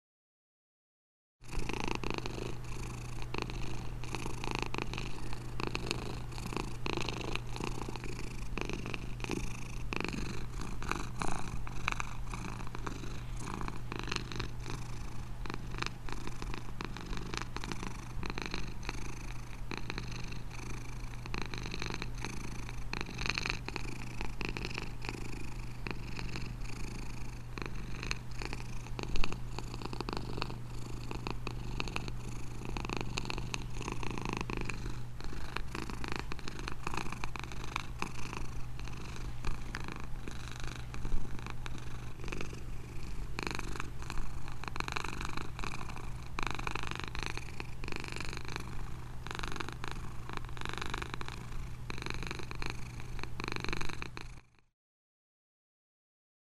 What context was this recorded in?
• Quality: High